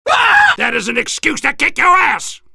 soldier_paincrticialdeath02.mp3